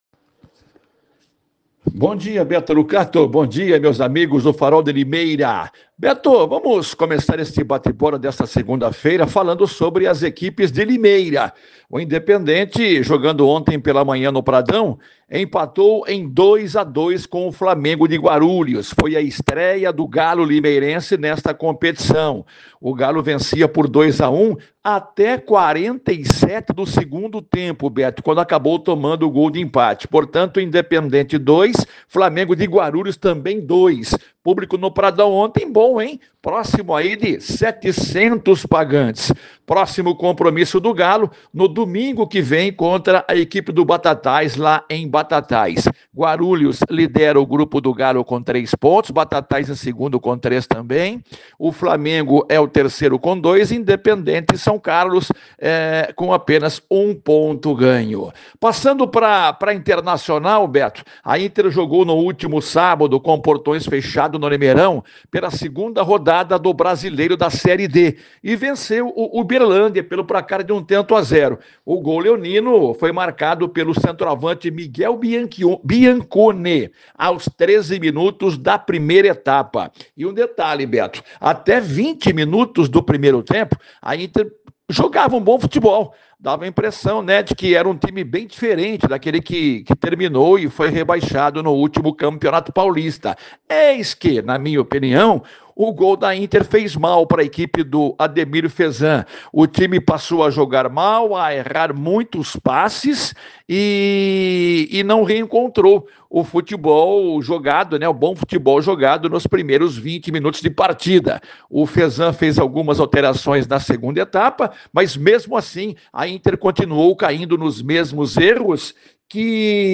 Jogando com os portões fechados no último sábado, 26, a Internacional venceu o Uberlândia por 1 a 0 e segue com 100% de aproveitamento no campeonato brasileiro da série D. Pela “bezinha”, o Independente estreou contra o Flamengo de Guarulhos no Pradão, empatando por 2 a 2. Confirma mais detalhes no boletim